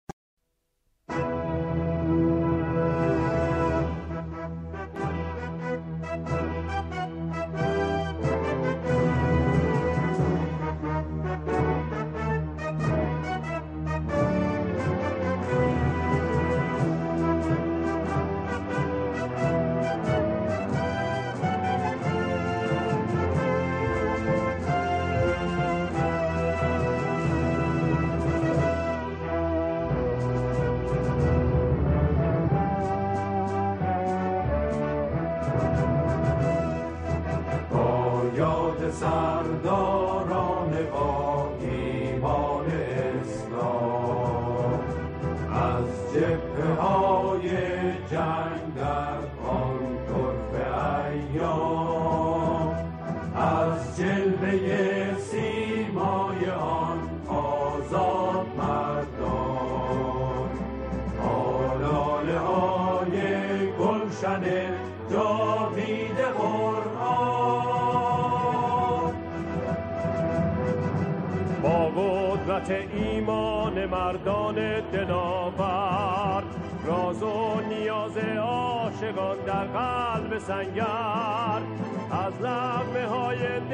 سرودهای شهدا
آنها در این قطعه، شعری را درباره شهدا همخوانی می‌کنند.